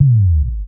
808-Kicks38.wav